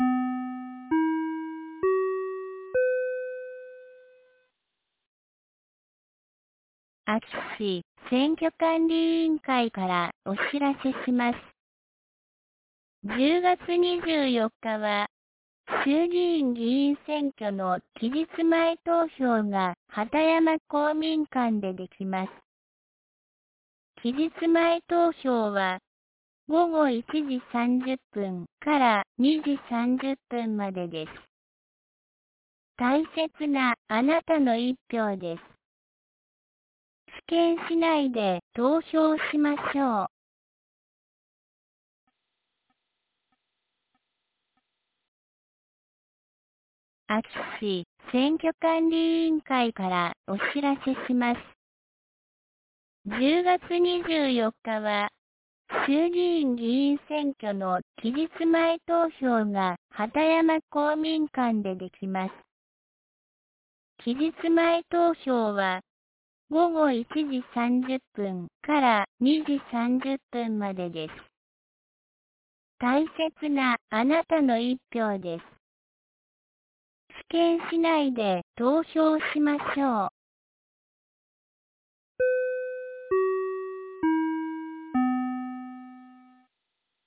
2024年10月23日 17時26分に、安芸市より全地区へ放送がありました。